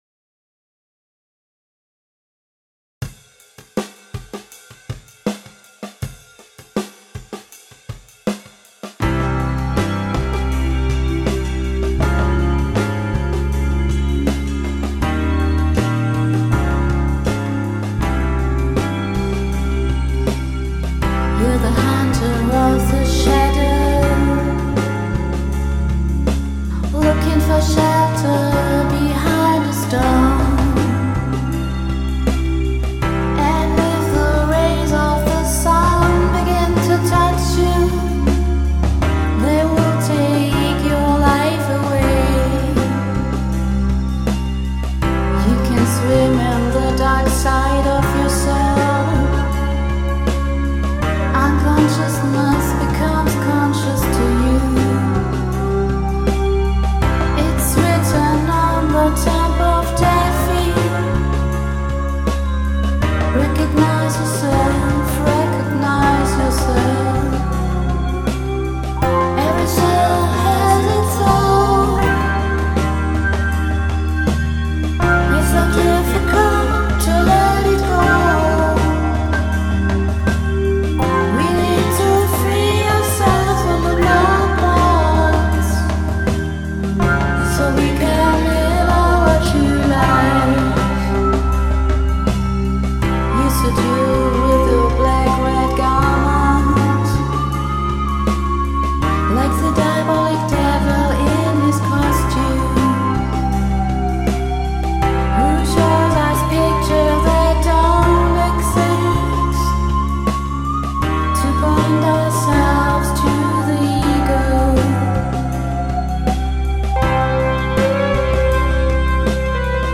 Astro-Pop for the New Age